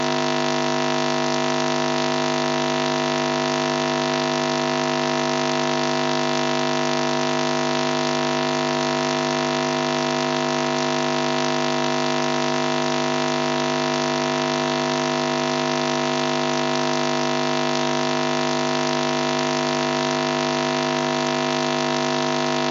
Changed pitch to original recording to perhaps decipher it better.
Listen to this recording for incoming chit chat, recorded and filtered to get to the subliminals.
All recordings made using an induction loop receiver that monitors magnetic modulations.